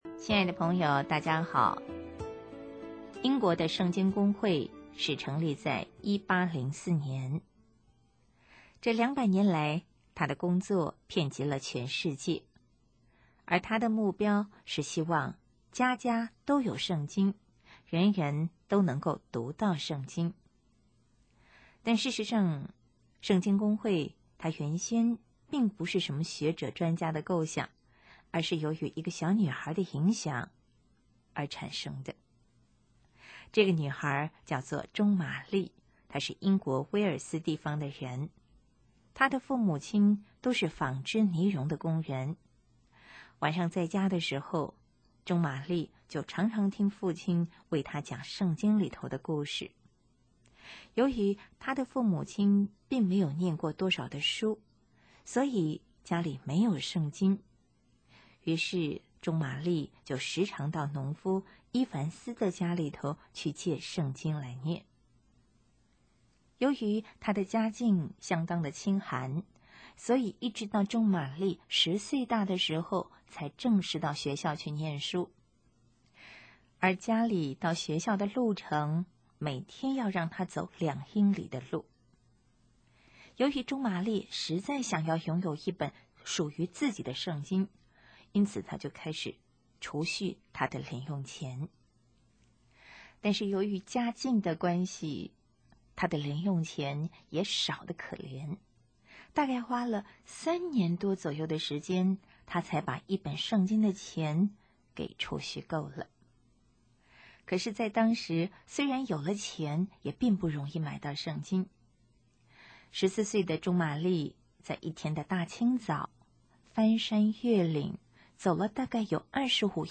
【儿童故事】|读经若渴